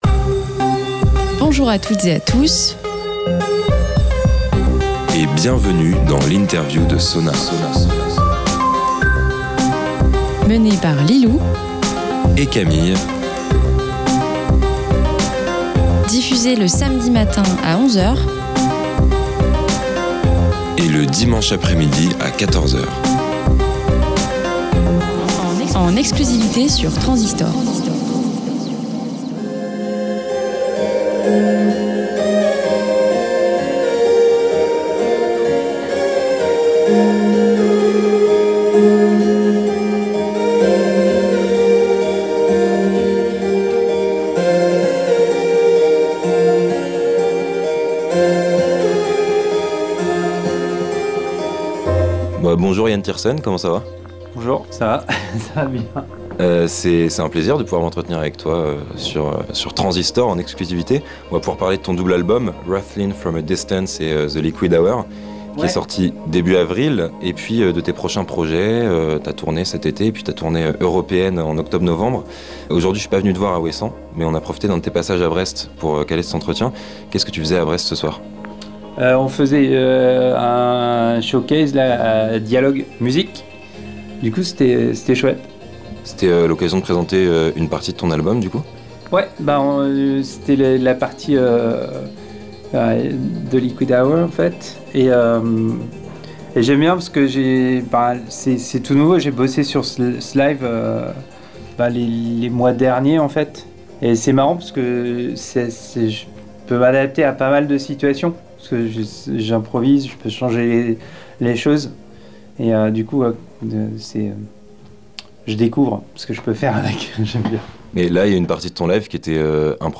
SONAR x INTERVIEW - Yann Tiersen